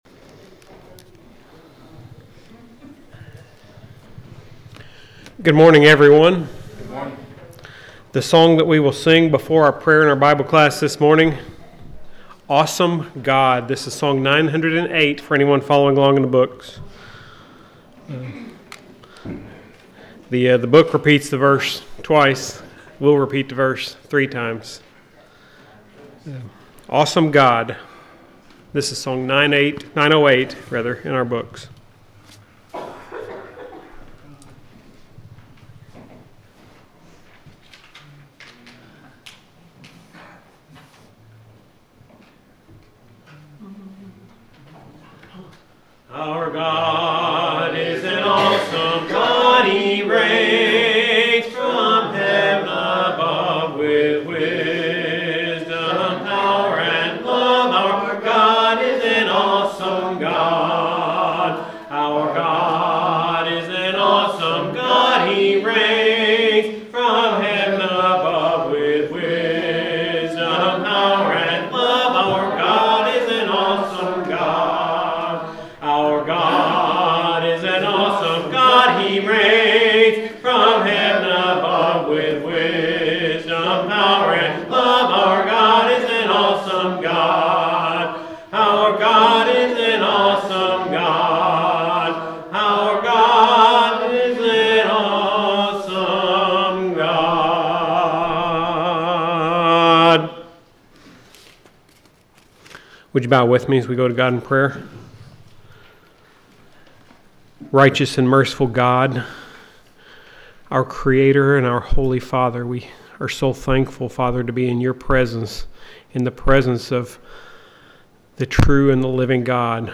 The sermon is from our live stream on 3/1/2026